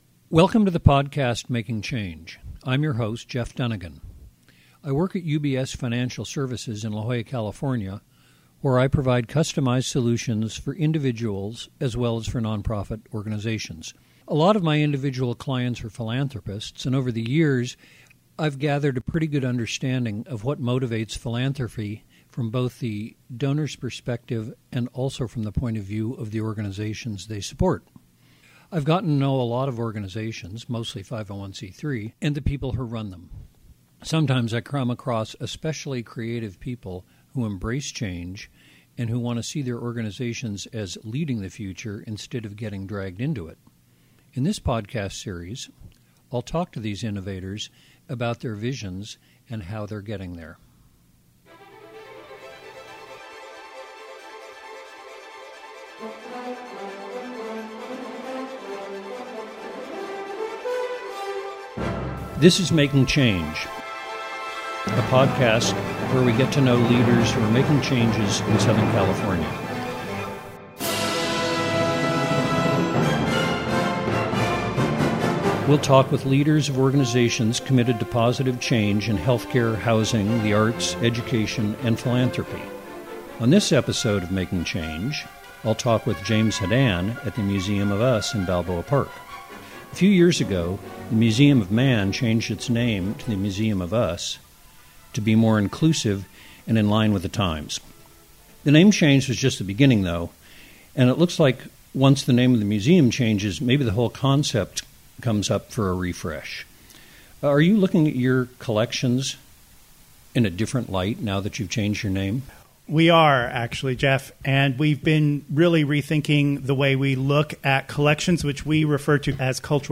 In the Making Change podcasts I interview the people who work at change-making organizations.